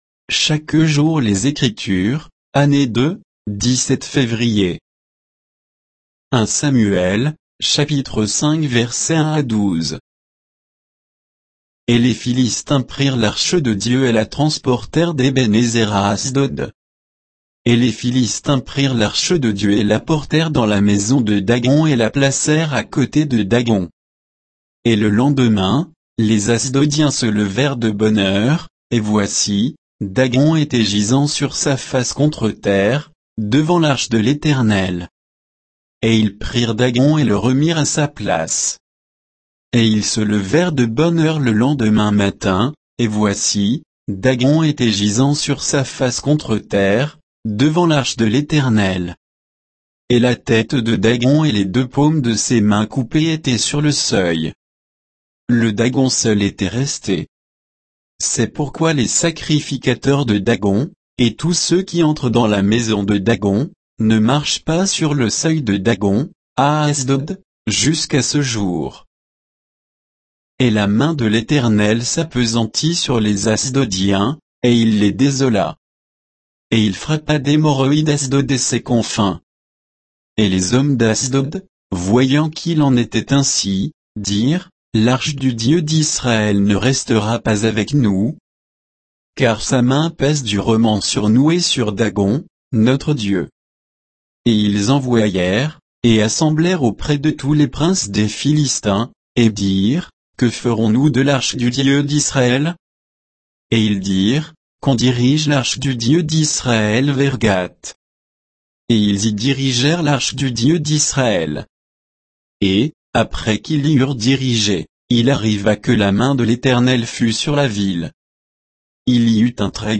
Méditation quoditienne de Chaque jour les Écritures sur 1 Samuel 5, 1 à 12